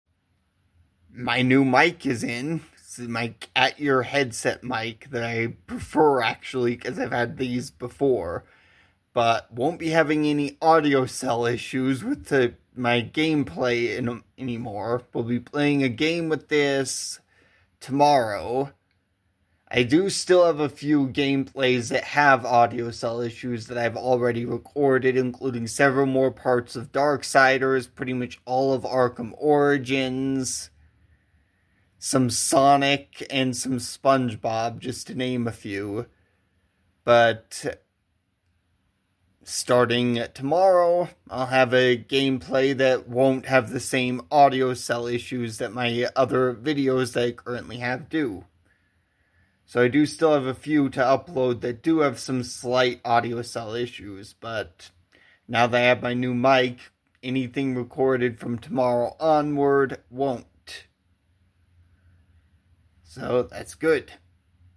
My new mic came in so hopefully there will be no more audiocel issues in future gaming video recordings